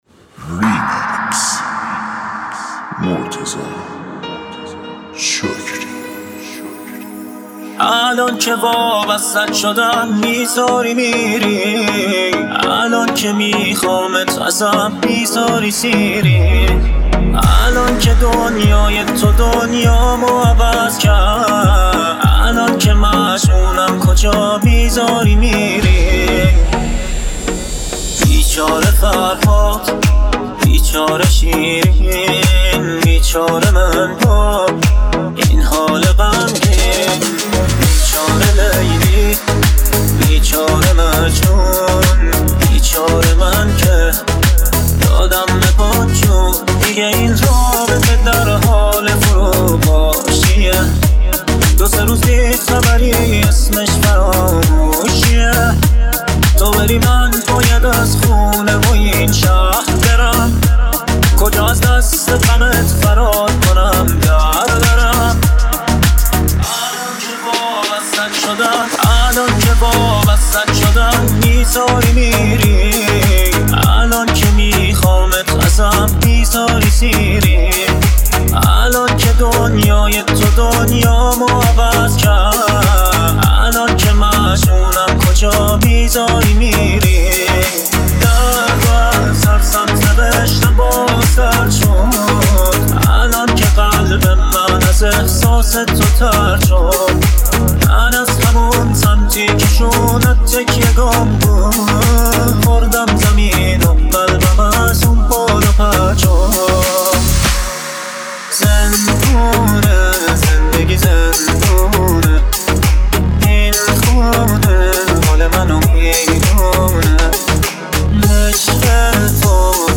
{بیس دار}